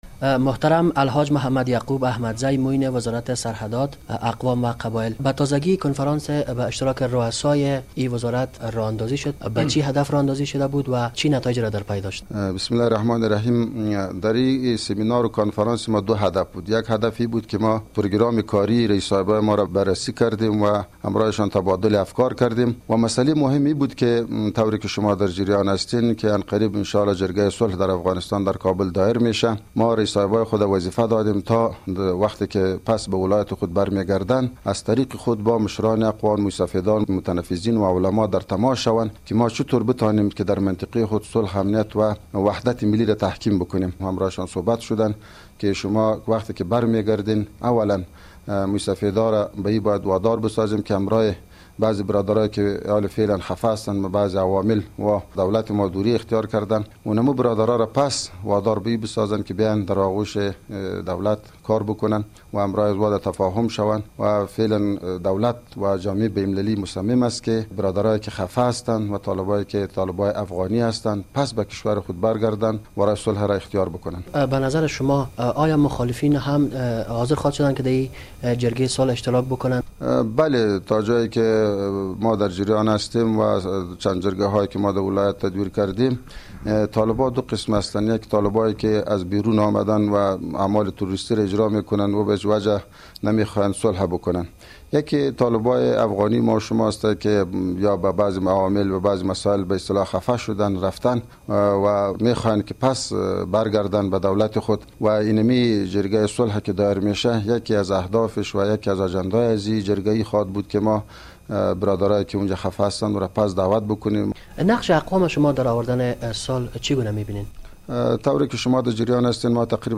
مصاحبه با یعقوب احمدزی معین وزارت سرحدات و اقوام در رابطه به صحبت با سران اقوام در مورد جرگه صلح